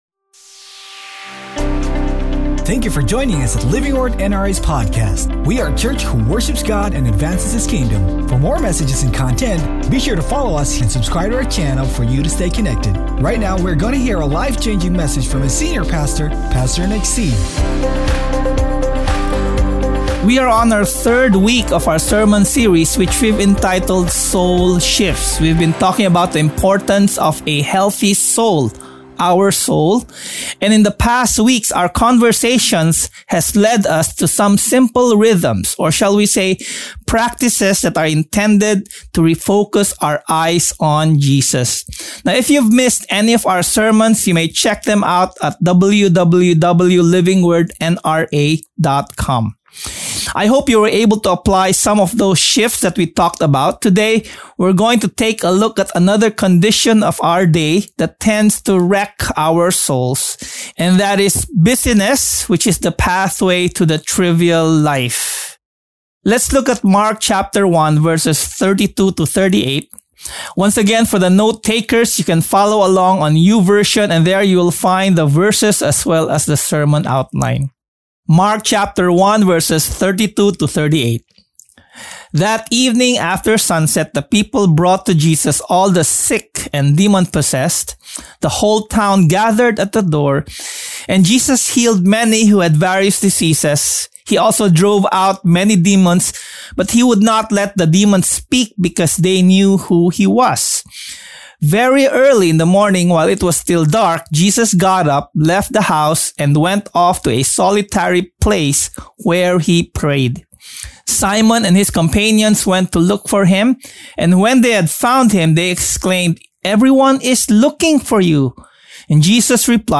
Worship Soul Shifts Mark Watch Listen Read Save We are so busy with so much pursuits that we don’t even notice the most important things slipping us by. Sermon